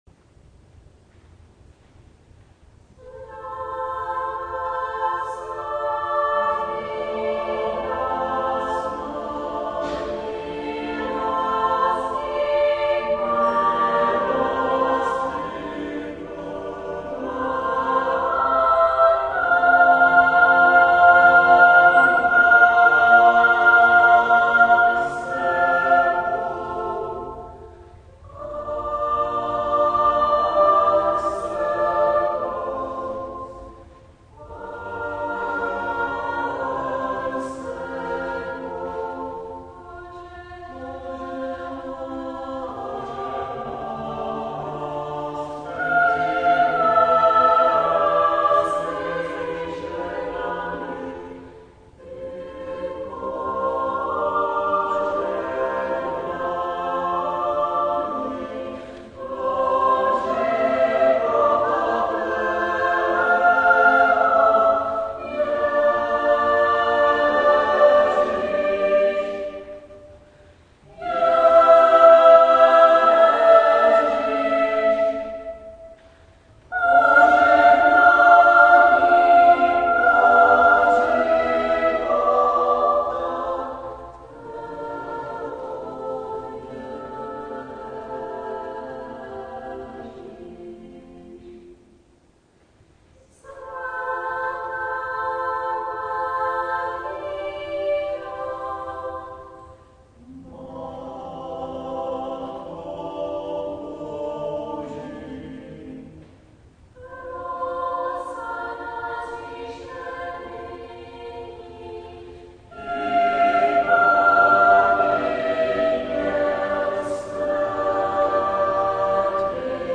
Smíšený pěvecký sbor SalvátorSalvátor
V této sekci najdete některé z amatérských nahrávek či videí našeho sboru.